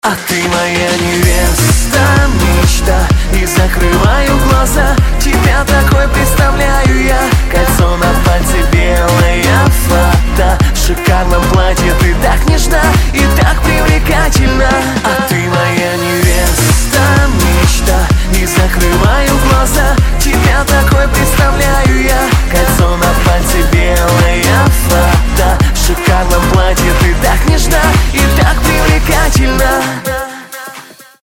позитивные
dance